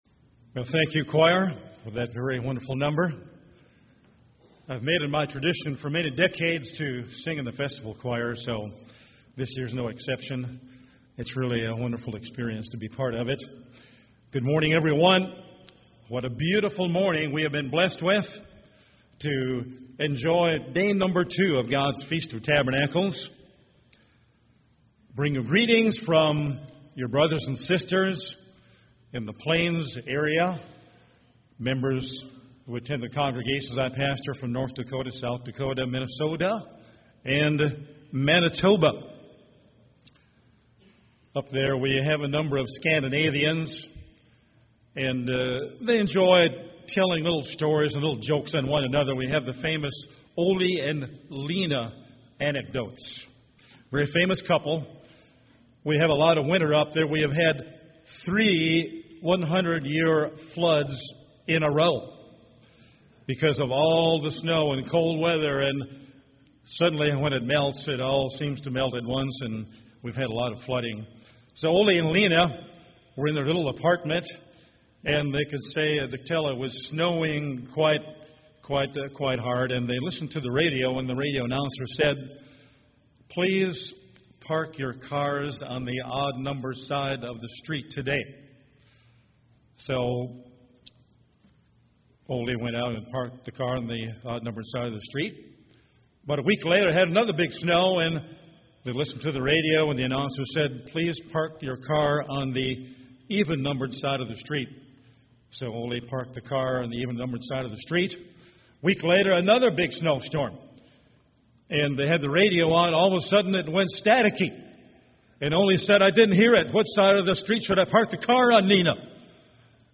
This sermon was given at the Panama City Beach, Florida 2011 Feast site.